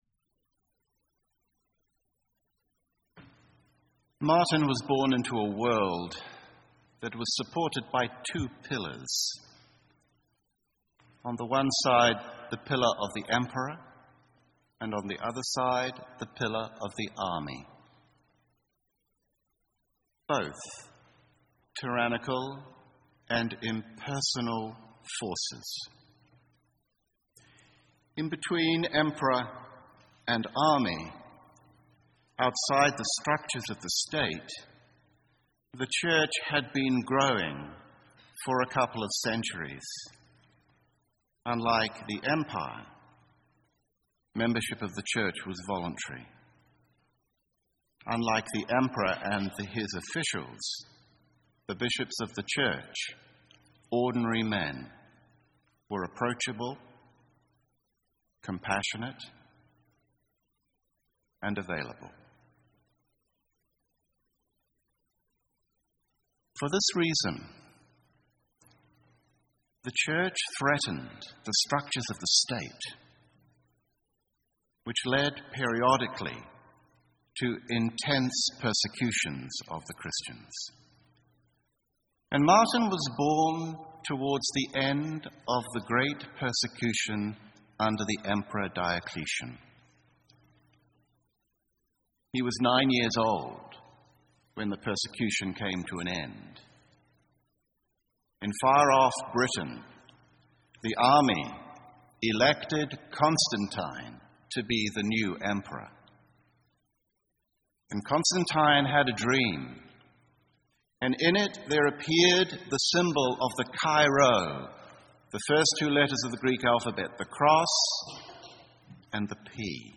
Here is the audio track from a reflection on Martin of Tour I gave at Choral Evensong on the occasion of their Patronal Festival at St Martin’s, Providence. The reflections are organized around the suggestion of an internal tension between love and duty that shaped the whole of Martin’s life.